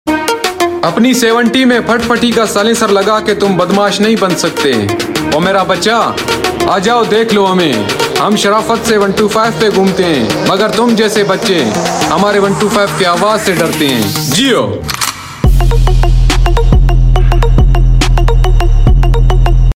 Honda 125 Bike Sound Effects Free Download